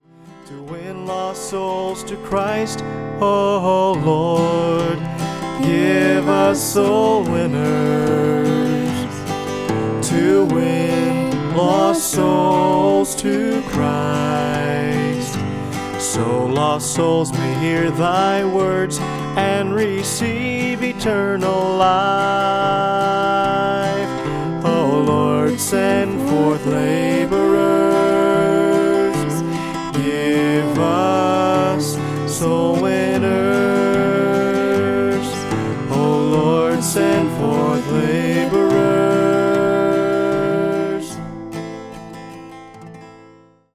on guitar
on ukulele